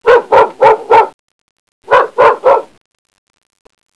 Bark
BARK.wav